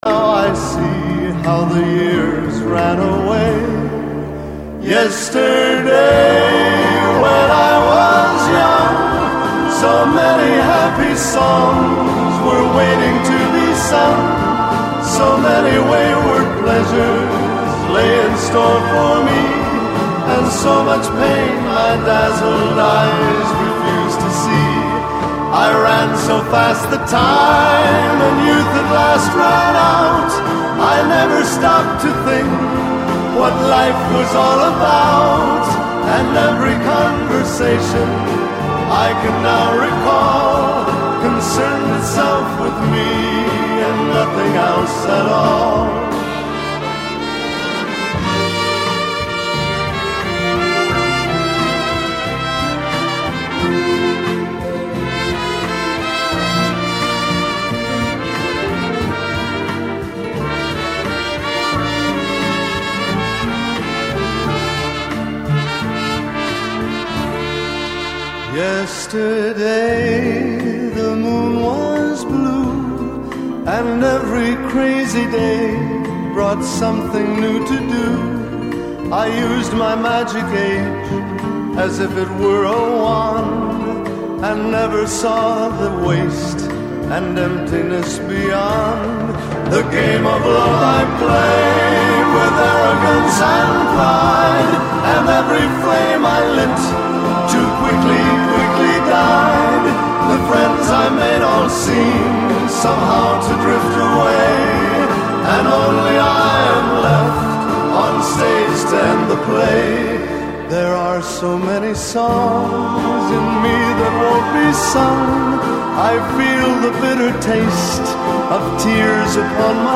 У него тоже идёт проигрыш труб как в отрывке.